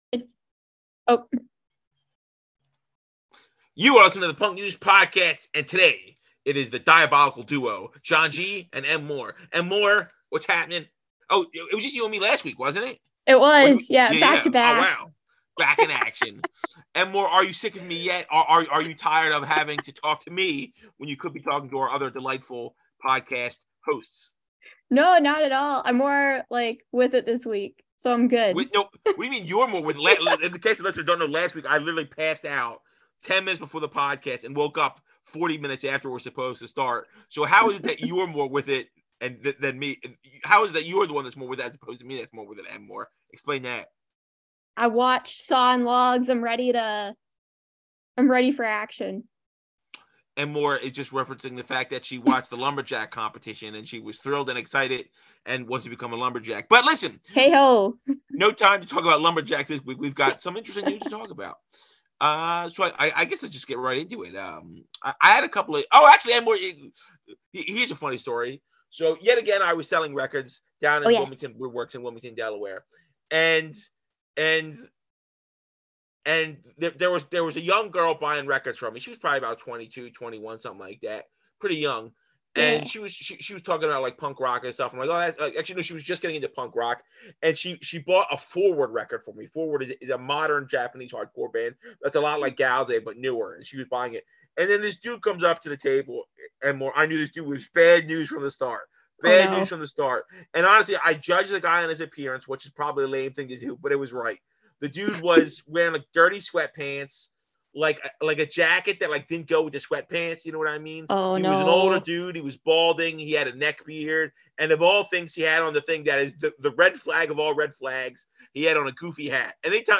This is a "rough mix".... deal with it!!!!